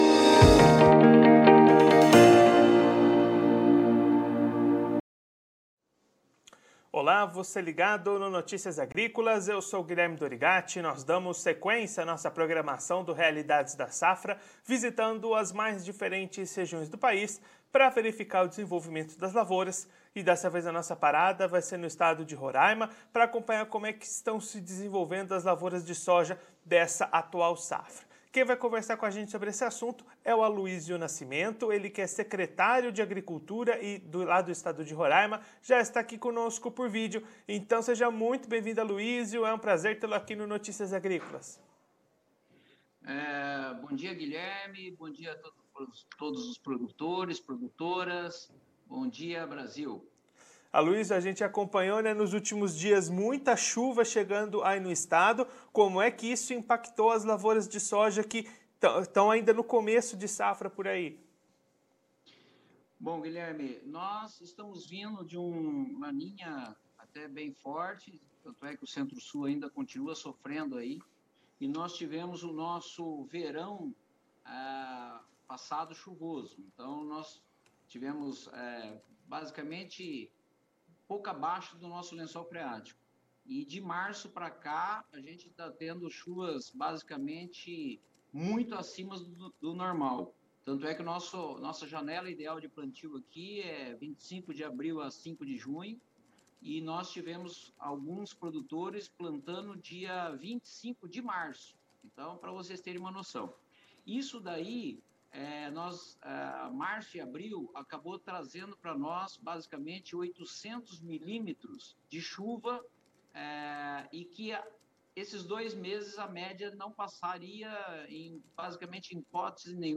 Confira a íntegra da entrevista com o Secretário de Agricultura do Estado de Roraima no vídeo.